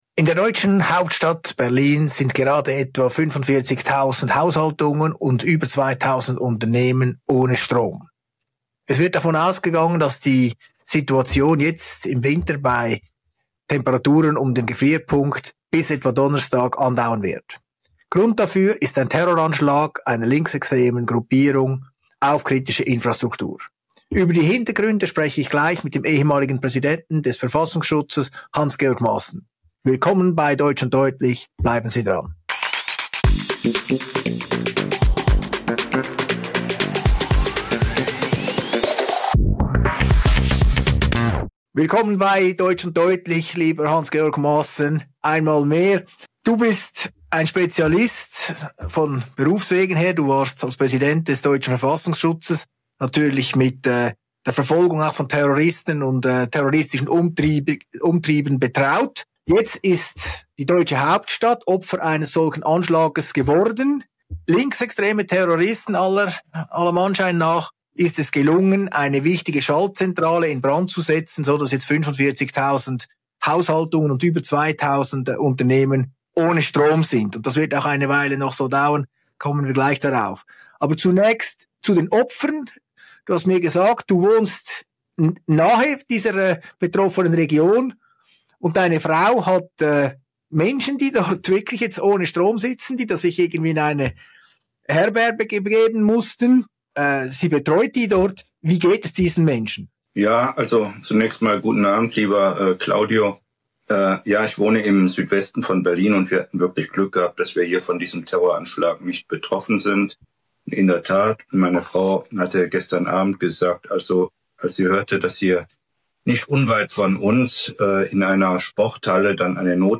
In einem ausführlichen Interview für «deutsch und deutlich» mit Claudio Zanetti analysiert der ehemalige Präsident des deutschen Verfassungsschutzes, Hans-Georg Maassen, den schweren linksextremistischen Terroranschlag auf eine Stromverteilstation in Berlin.